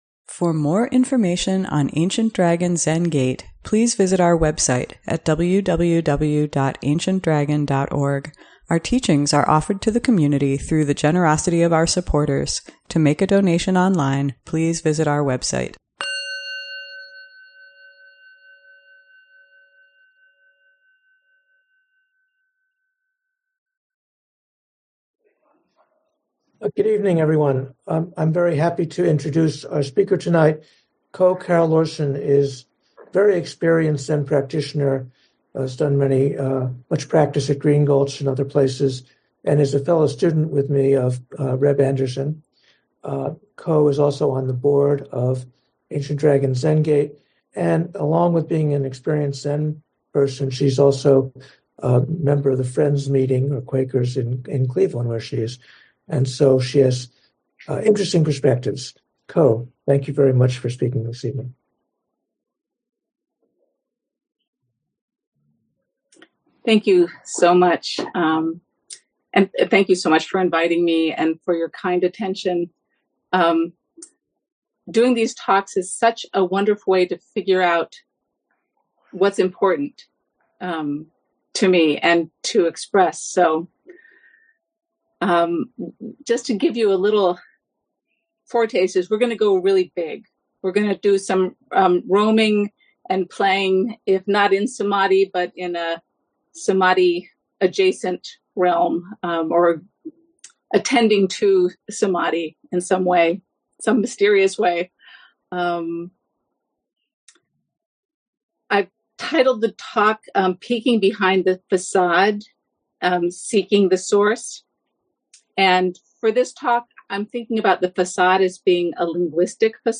ADZG Monday Night Dharma Talk